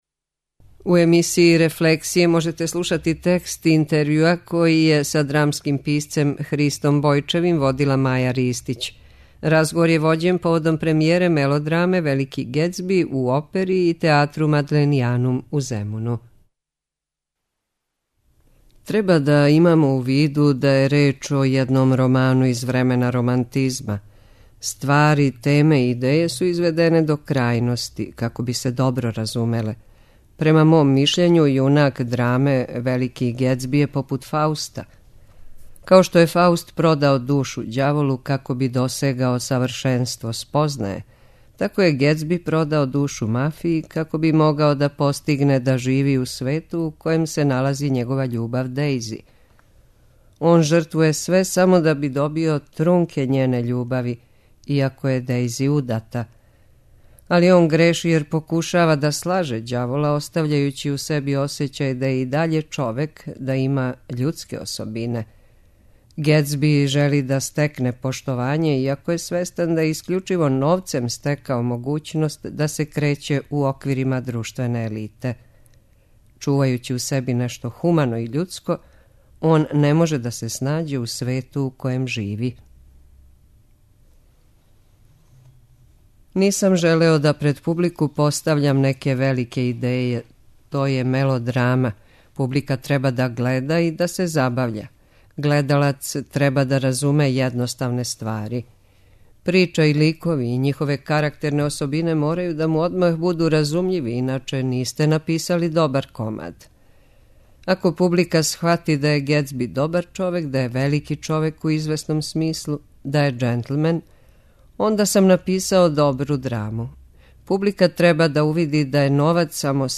Разговор је вођен поводом премијере мелодраме Велики Гетсби у Опери и театру Мадленијанум у Земуну.